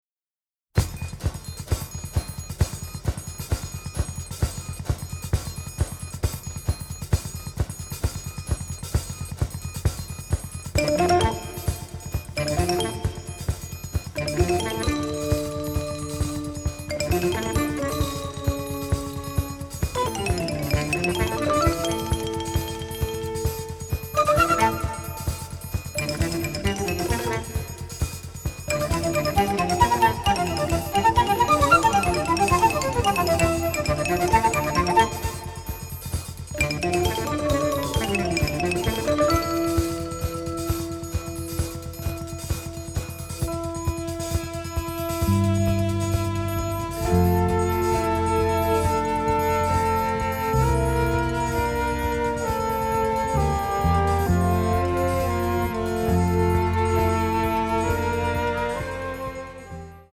romantic, innovative, masterful score